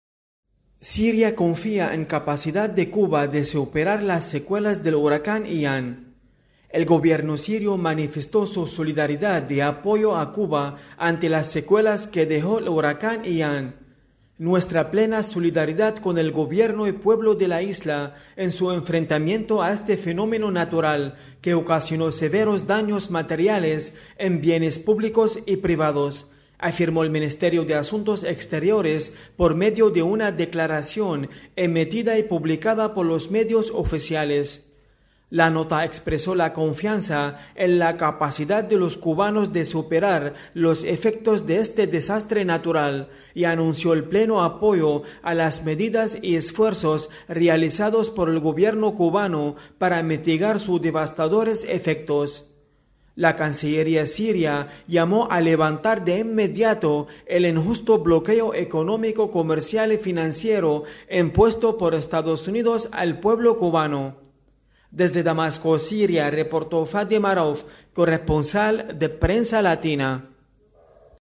desde Damasco